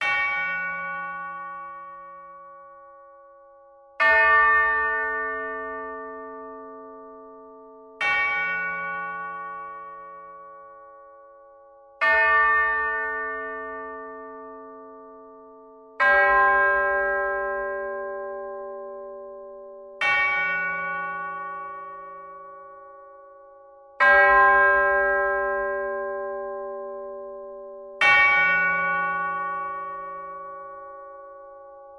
Звук для драматической сцены — Куранты, колокола